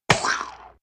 splat6.ogg